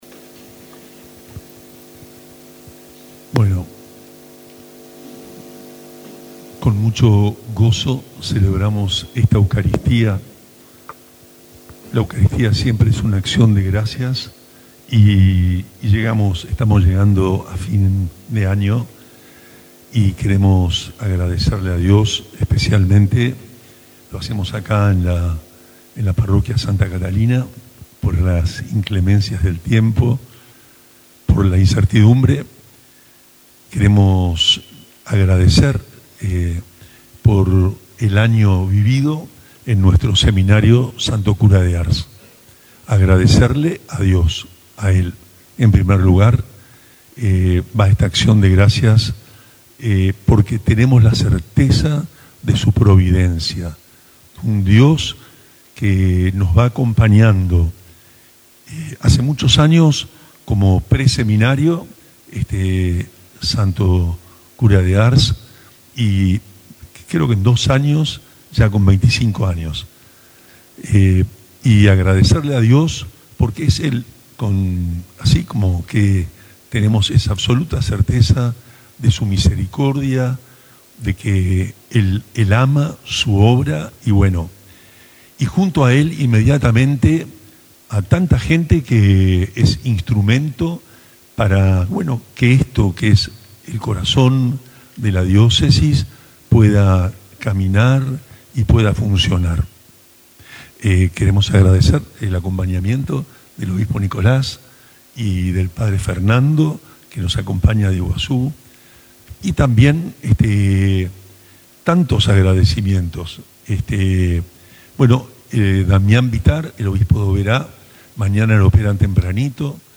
El Seminario Diocesano Santo Cura de Ars celebró este lunes una Misa de Acción de Gracias en la parroquia Santa Catalina, debido a las inclemencias del tiempo que impedían realizarla en el patio del seminario. La Eucaristía fue presidida por el obispo de la diócesis, monseñor Juan Rubén Martínez, y contó con la participación de seminaristas, sacerdotes y miembros de la comunidad, quienes se acercaron para compartir un momento de oración y gratitud al finalizar el año.
homilia-monse-misa-accion-de-gracias-15-diciembre.mp3